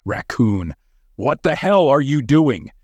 His voice, low and compelling, carries a cunning edge. Deliberately paced, it weaves through conversations with a sly undertone, betraying a manipulative and scheming character.